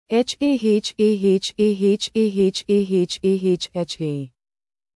Hahahahahahahha Botão de Som
hahahahahahahha.mp3